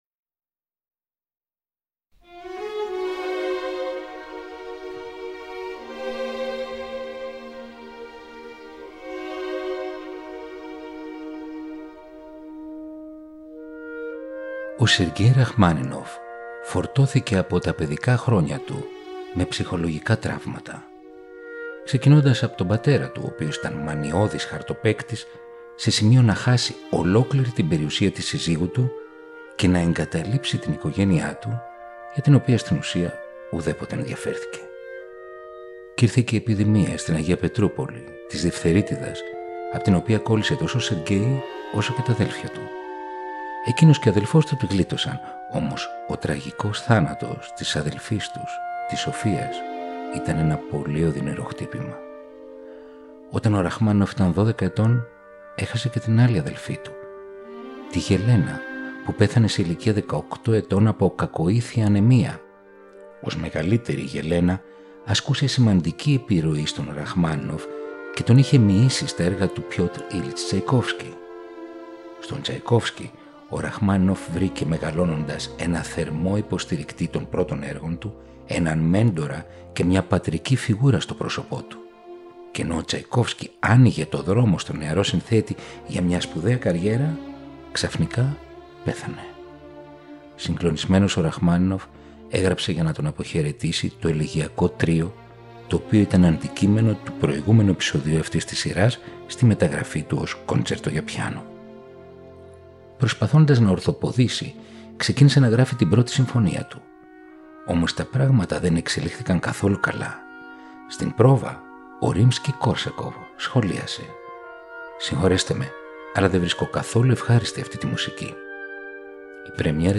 150 χρόνια από τη γέννηση του Σεργκέι Ραχμάνινοφ. Έργα για πιάνο και ορχήστρα.